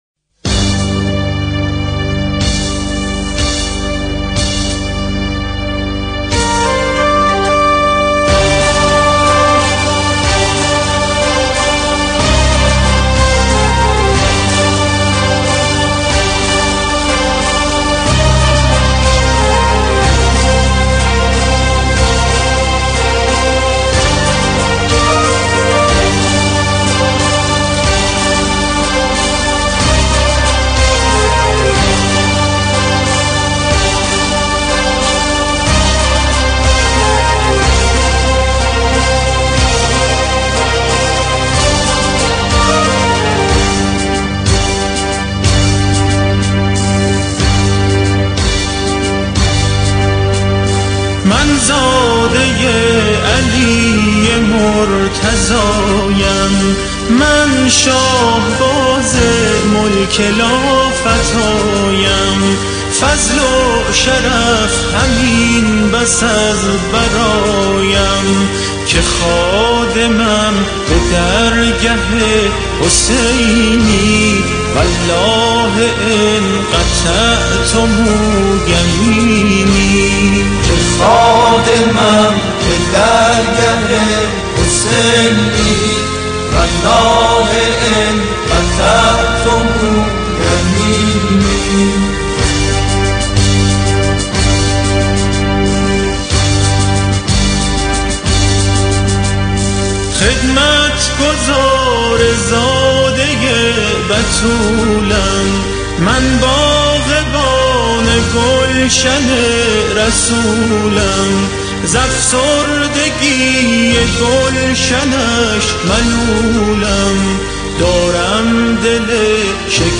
قطعه موسیقی عاشورایی
در قالب رجزخوانی جنگی بازخوانی می‌شود.